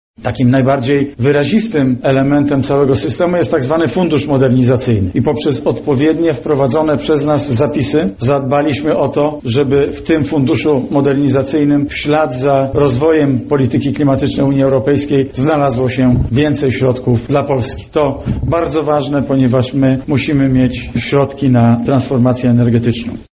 Jestem zadowolony z osiągniętego kompromisu – mówi Premier Mateusz Morawiecki:
morawiecki-po-szczycie.mp3